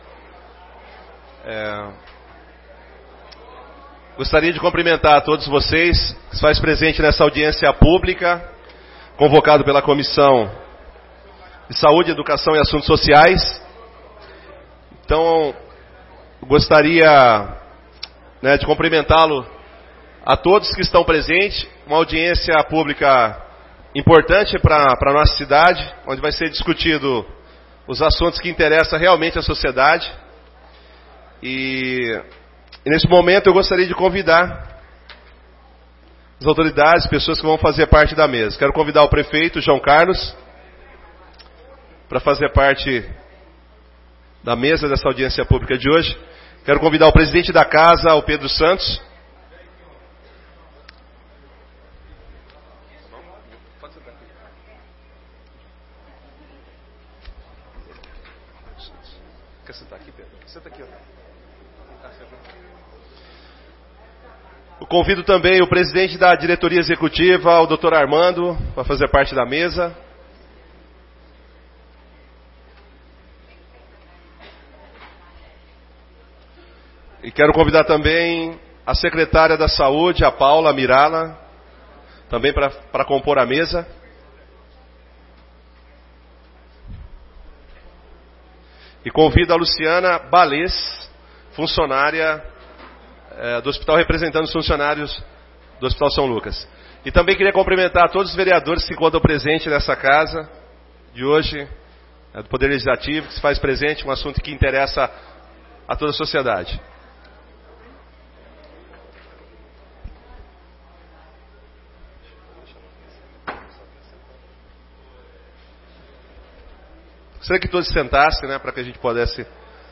Audiência Pública sobre a intervenção da Prefeitura no Hospital São Lucas